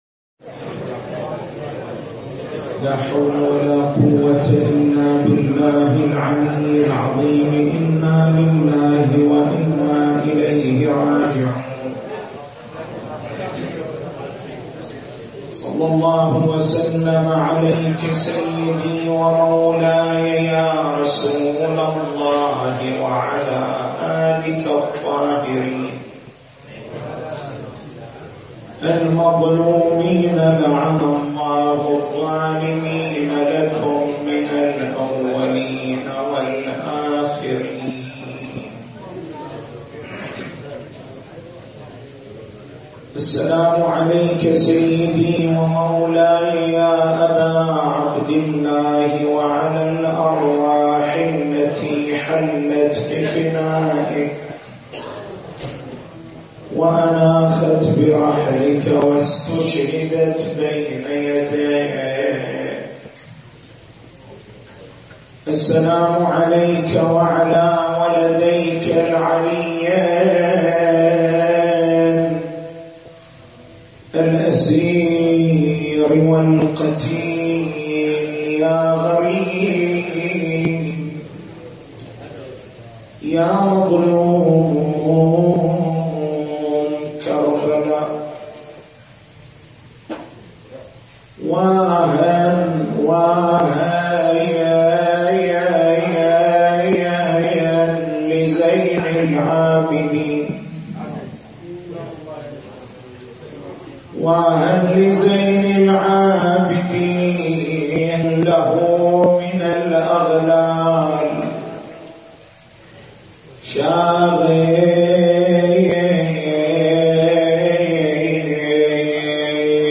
محاضرة ليلة 25 محرم 1436 - من هي أمّ الإمام زين العابدين (ع)؟